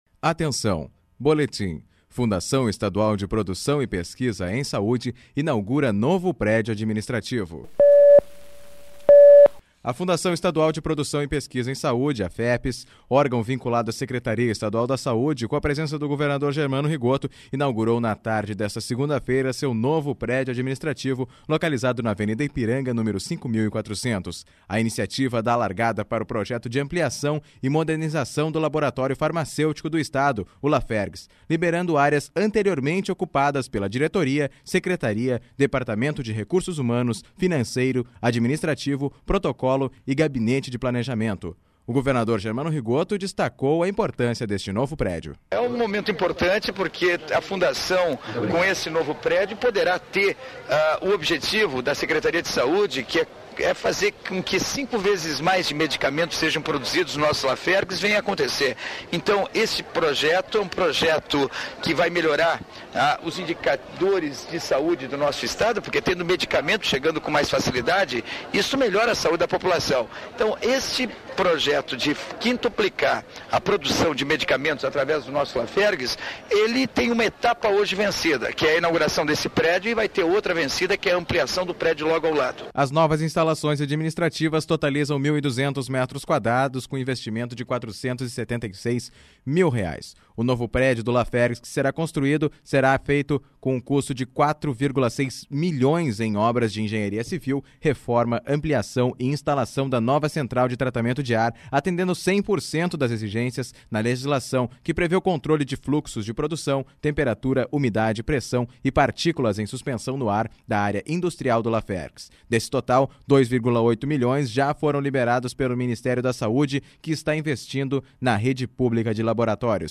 A Fundação Estadual de Produção e Pesquisa em Saúde, inaugurou na tarde desta segunda-feira seu novo prédio administrativo. (Sonora: Governador Germano Rigotto)